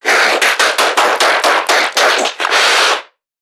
NPC_Creatures_Vocalisations_Infected [59].wav